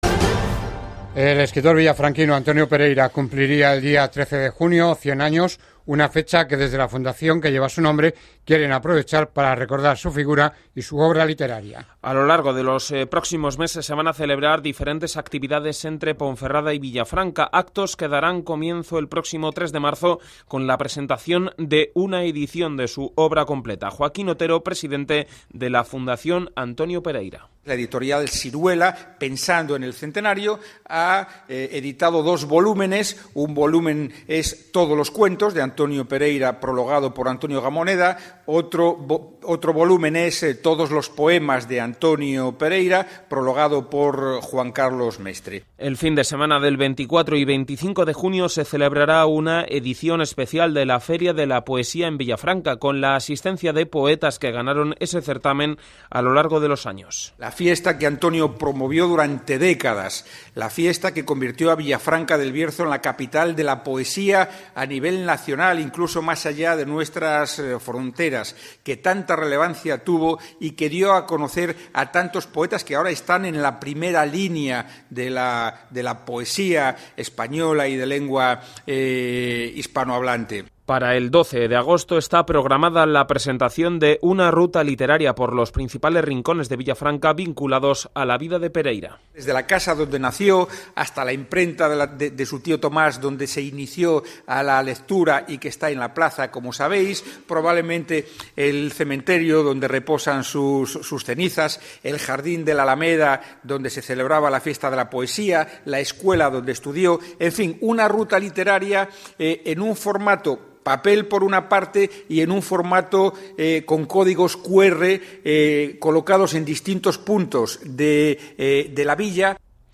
Castilla y León Informativos. El escritor villafranquino Antonio Pereira cumpliría el próximo 13 de junio 100 años. Una fecha que desde la Fundación que lleva su nombre quieren aprovechar para recordar su figura personal y su obra literaria con la celebración de diferentes actividades entre Ponferrada y Villafranca del Bierzo. Actos que darán comienzo el próximo 3 de marzo con la presentación de una edición de su obra completa.